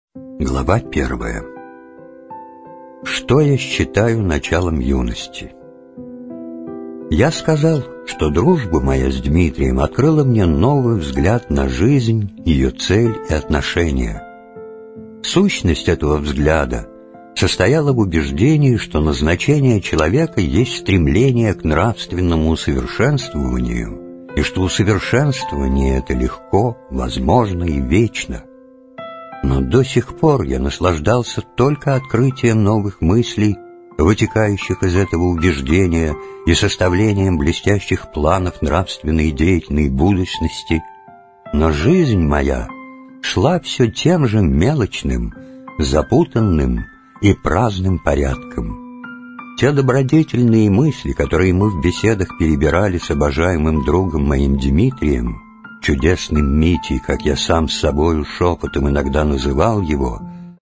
Аудиокнига Юность | Библиотека аудиокниг